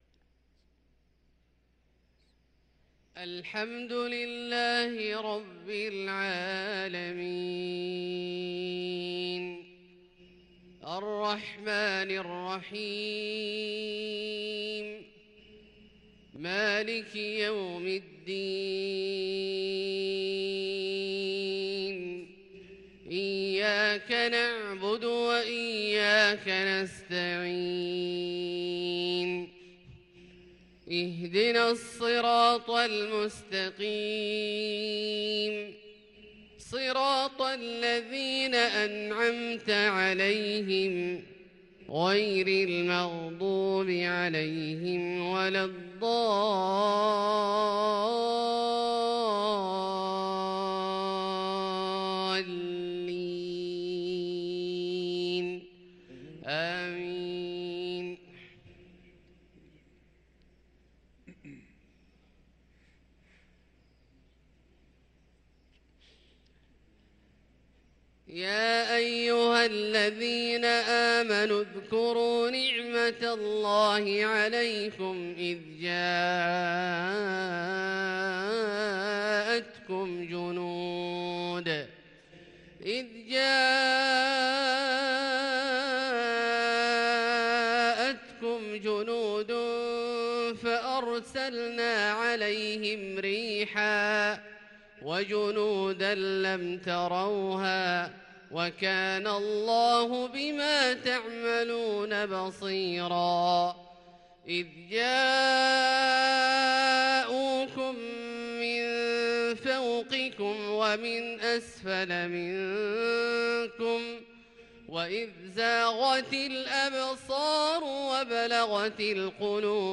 صلاة الفجر للقارئ عبدالله الجهني 27 جمادي الأول 1444 هـ
تِلَاوَات الْحَرَمَيْن .